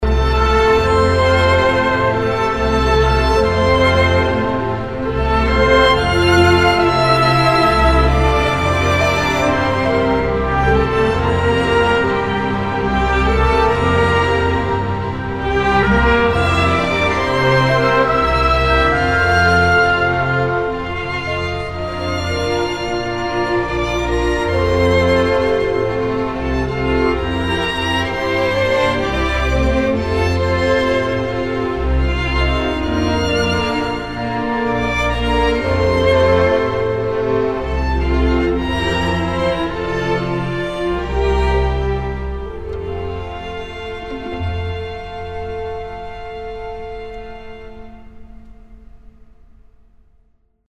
For more than 40 years, each time a child is born, Jackson-Madison County General Hospital has played the Public Domain of Brahms Lullaby – a number that reaches – on average – a staggering 3,400 times each year.
“In our September concert, we pulled our orchestra together and they performed this a couple of times in recordings so we could have this special event.